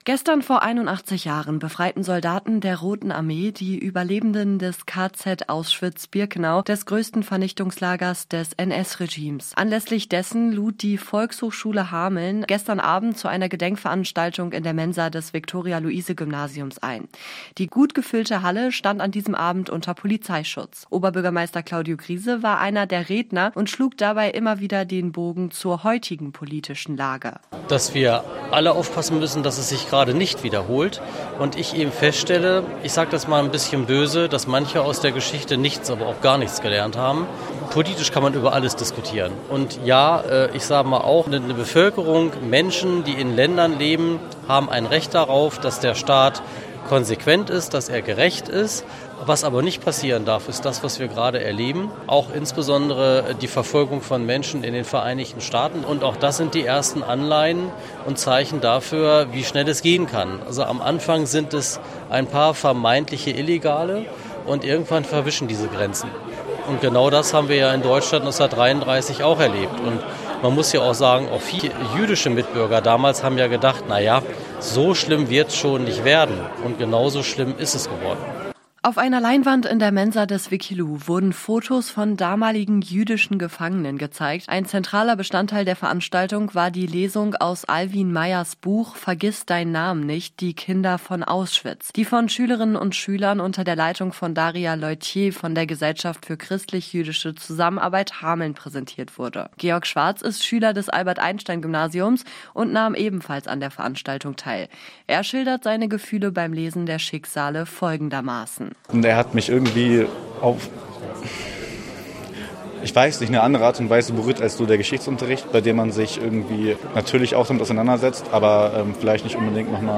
Hameln: Eindrücke von der Gedenkstunde für die Opfer des NS-Regimes